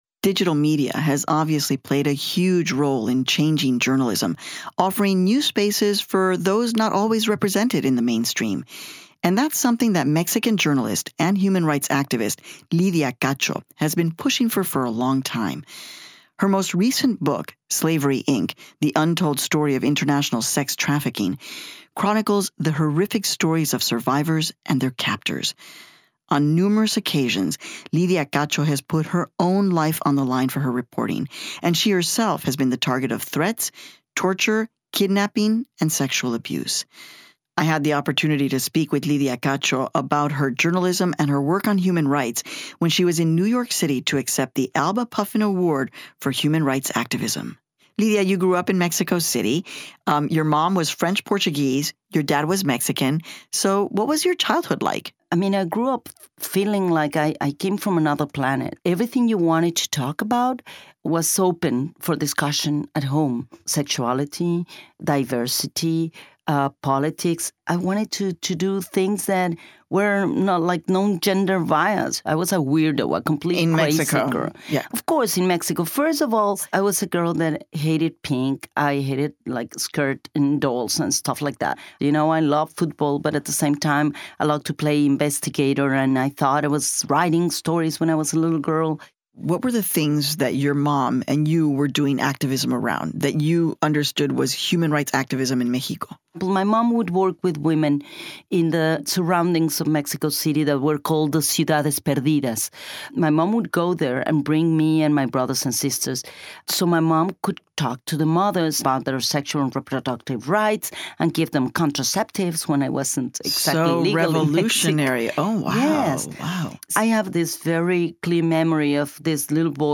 We interview Mexican journalist Lydia Cacho on her reporting and human rights activism. She is most famous for exposing child sex trafficking and abuse at the highest levels of government in Mexico.